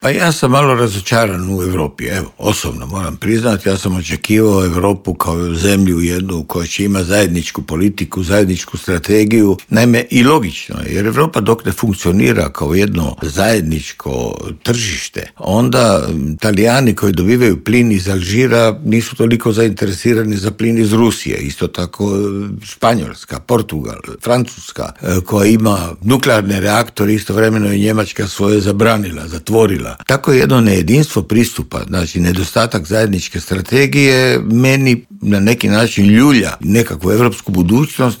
ZAGREB - Dok svijet pozorno prati hoće li doći do mirnog okončanja rata u Ukrajini, energetski stručnjak Davor Štern u Intervjuu Media servisa poručuje da bi u slučaju ublažavanja europskih sankcija trebalo dogovoriti fiksirane cijene nafte i plina.